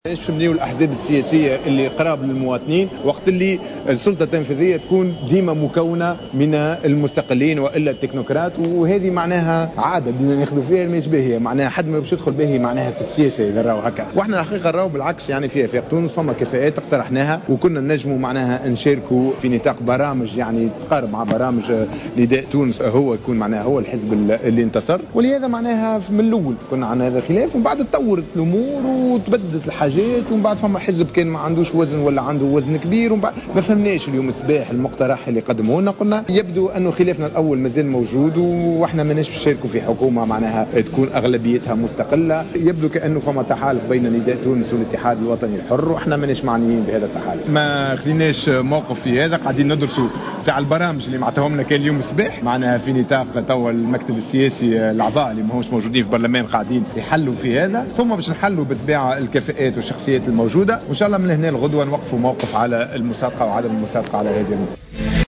قال رئيس حزب آفاق تونس ياسين ابراهيم في تصريح ل"جوهرة أف أم" إن آفاق تونس رفض المشاركة في حكومة تكون أغلبيتها مستقلة.